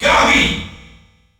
The announcer saying Kirby's name in French releases of Super Smash Bros.
Kirby_French_Announcer_SSB.wav